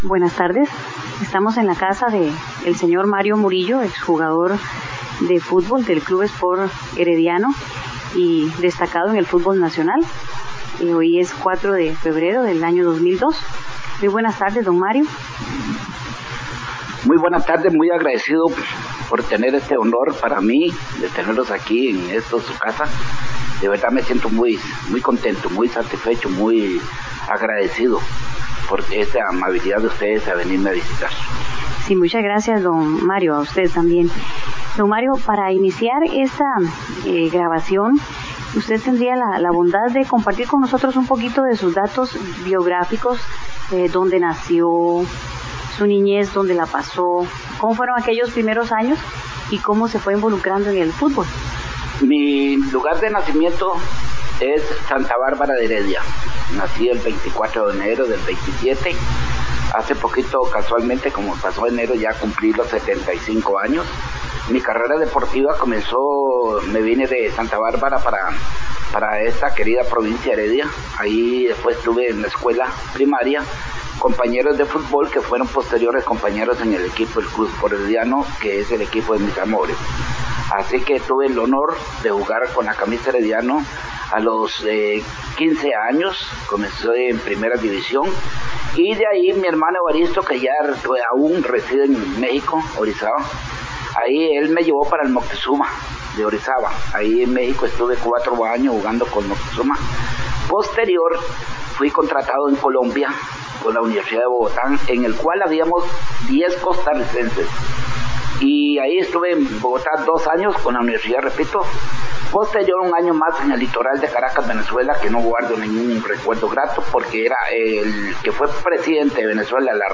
Entrevistas
mesa redonda sobre el Fútbol y entrevista a Guadalupe Urbina
Notas: Casete de audio y digital